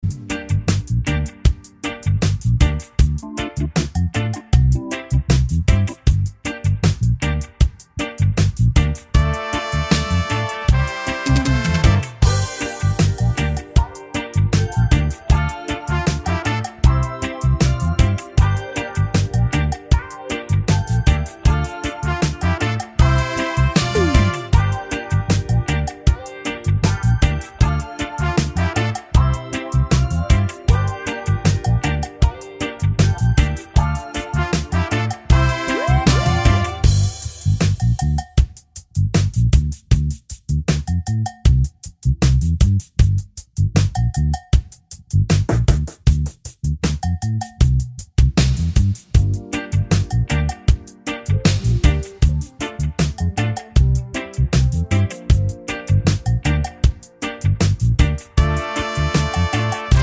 no Backing Vocals Reggae 4:09 Buy £1.50